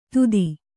♪ tudikālu